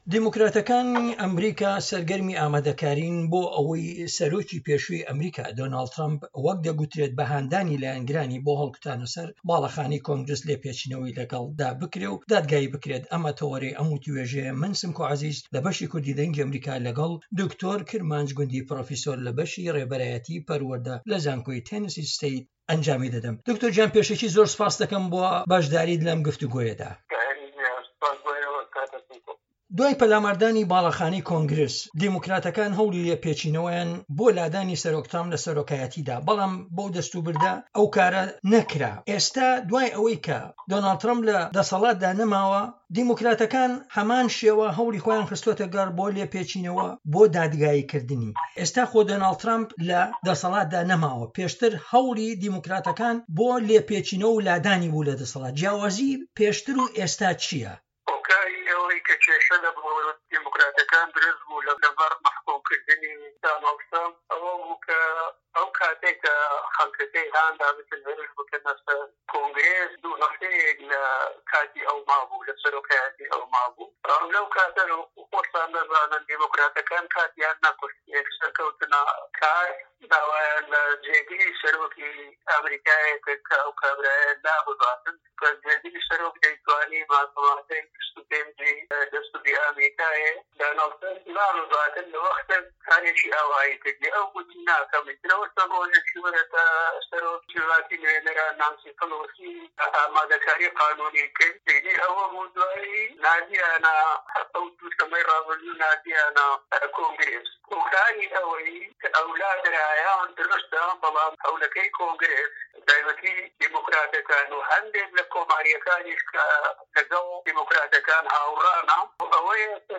دەقی وتووێژی